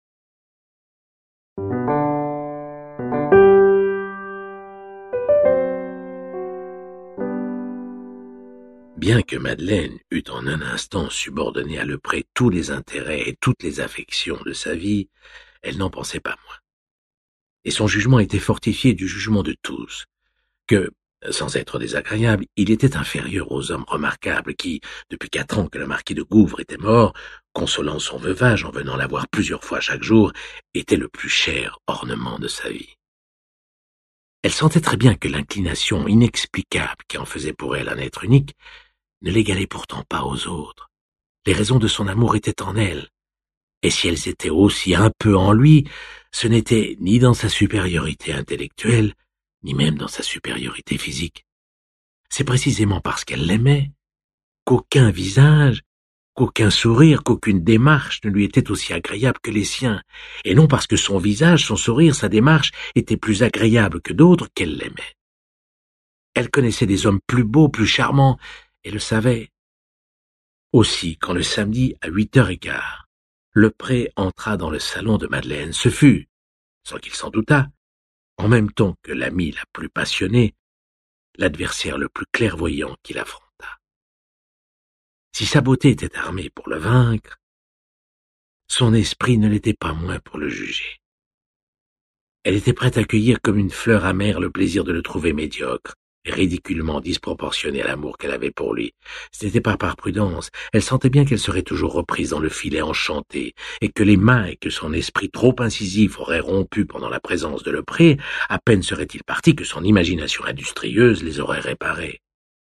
Diffusion distribution ebook et livre audio - Catalogue livres numériques
La lecture d’André Dussollier, délicieuse et rare comme les parfums du texte "Souvenir", éclaire quelques uns des mystères qui font le charme de Proust , et laissera en vous sa trace.